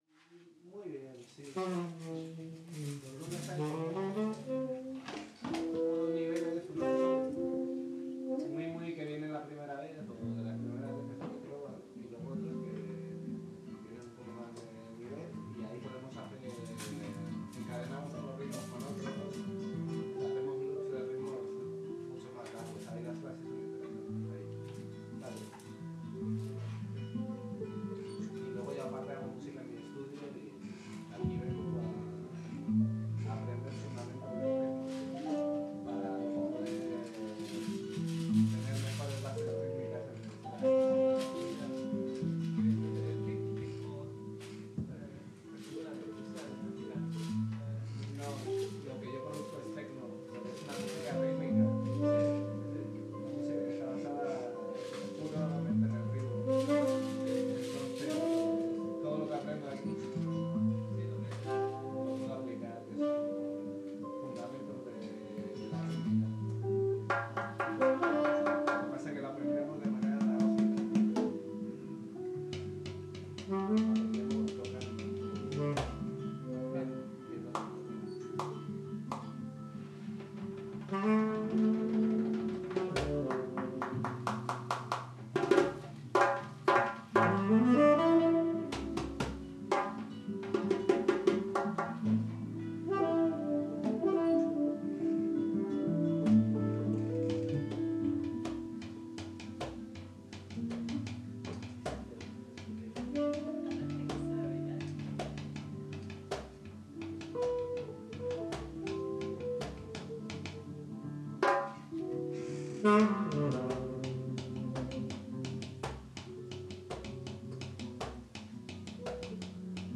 Music from the performances when the autumn leaves fall and the Future Machine weather jams in Finsbury Park, featuring Future Machine, Finsbury Park Drumming School and the rainmakers
Future-Machine-Jam-Jan-2023-Jam-1-warmup.mp3